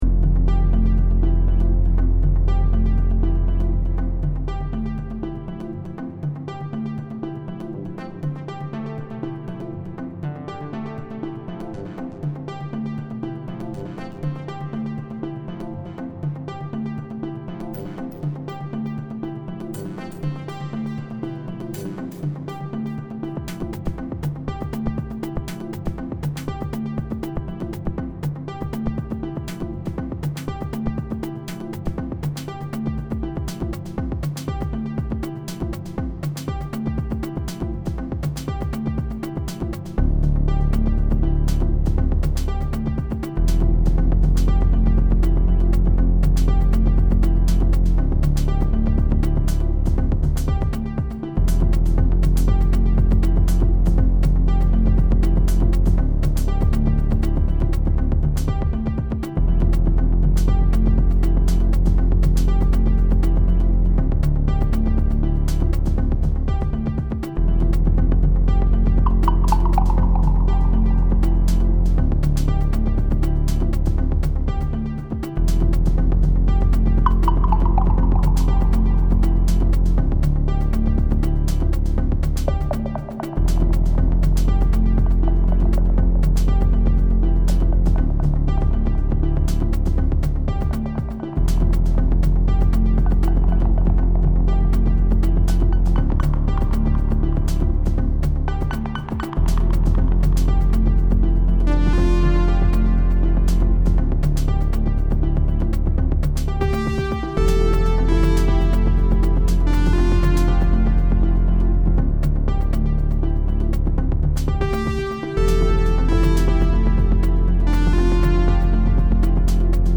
Just to jump in this band wagon….a4 mk1 jam with aforementioned impossible bass sounds